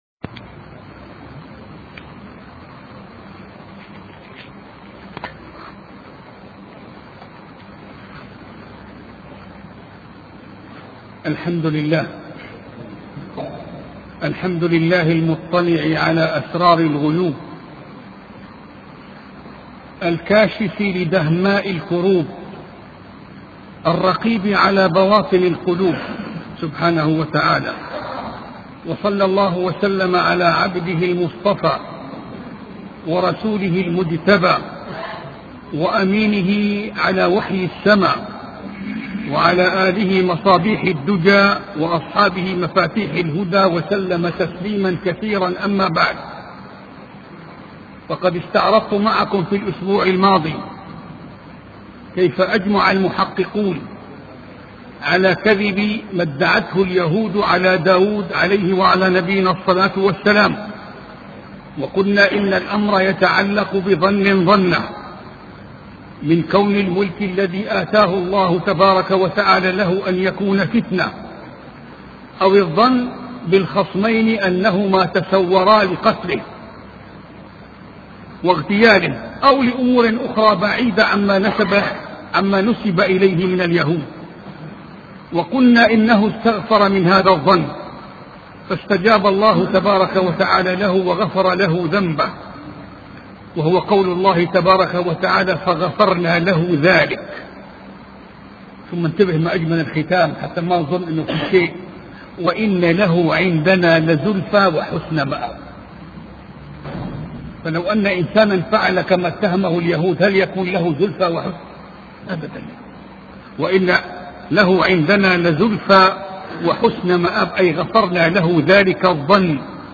سلسلة محاضرات في قصة داود عليه السلام